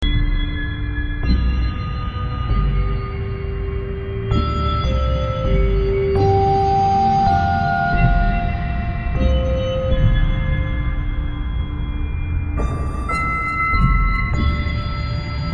僵尸没有叫声
描述：为创造一种阴森恐怖的气氛而制作的Fx，纯粹是由我的人声制作的，这首歌的原始版本有一种尖叫声，这个版本没有。
标签： 120 bpm Weird Loops Fx Loops 2.61 MB wav Key : Unknown
声道立体声